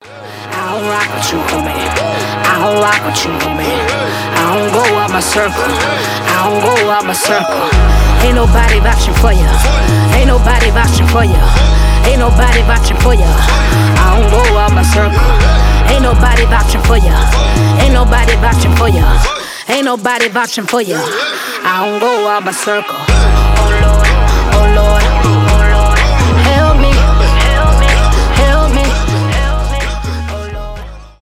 басы , рэп , ритмичные